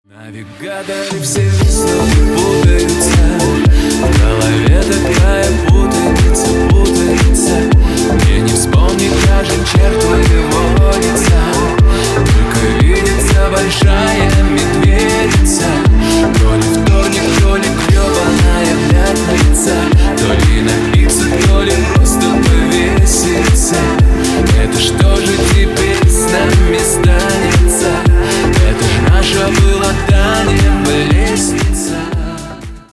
• Качество: 192, Stereo
поп
мужской вокал
грустные